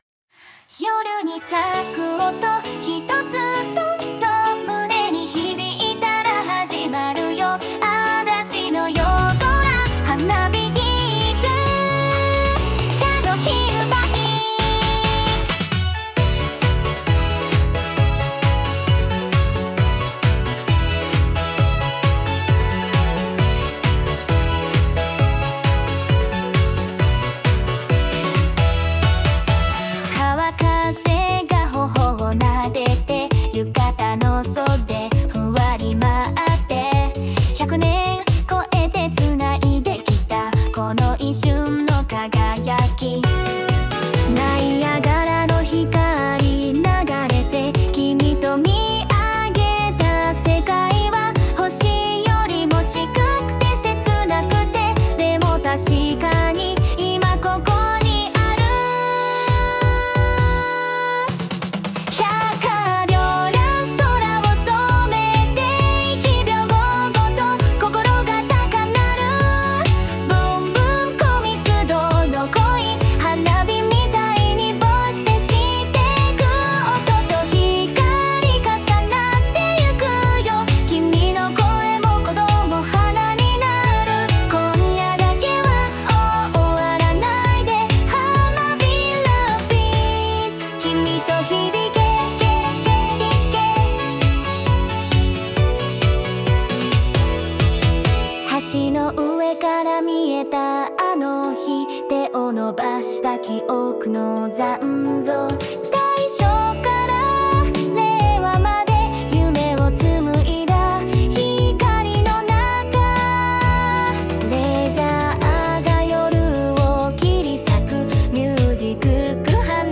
作曲：最新AI